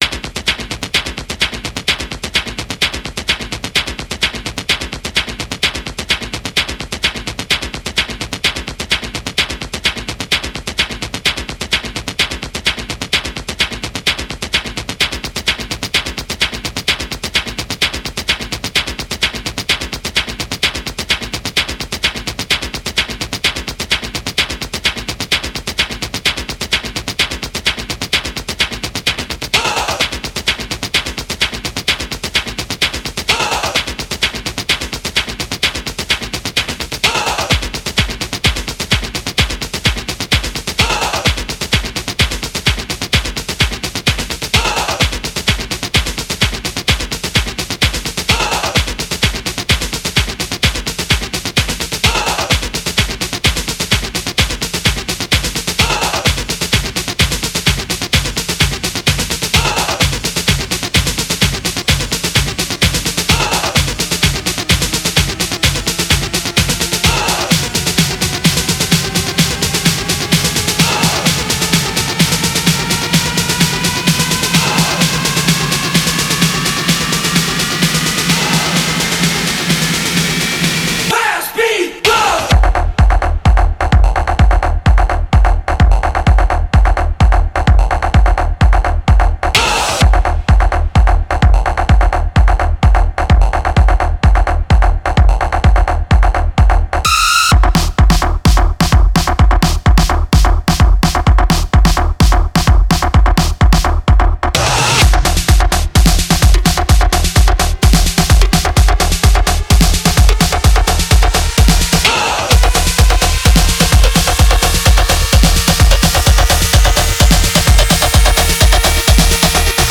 house anthems